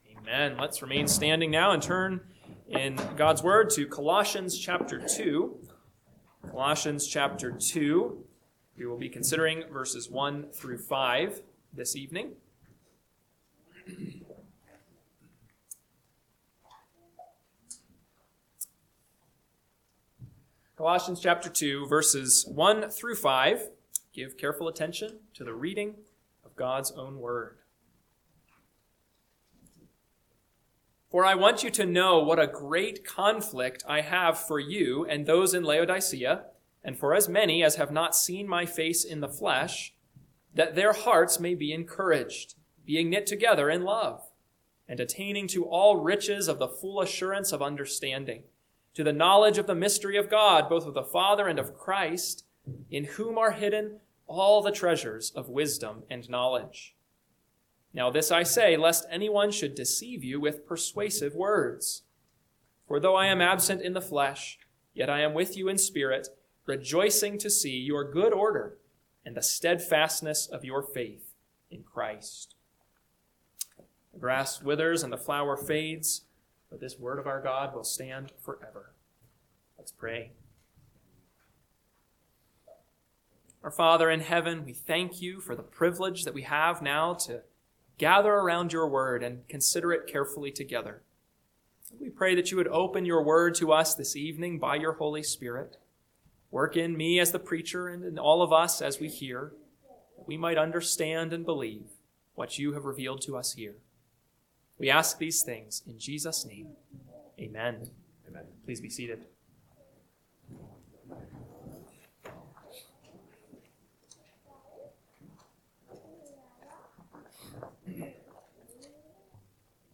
PM Sermon – 2/15/2026 – Colossians 2:1-5 – Northwoods Sermons